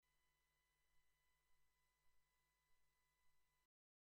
Aufnahmeprobleme (Pfeifen/Piepsen)
Bei jeder Audioaufnahme tritt so ein Piepsen/Pfeifen (durchgehender hoher Sinuston) auf und ich krieg ihn nich weg.
man muss schon ziemlich aufdrehen um es zu hören.
test_pfeifen.mp3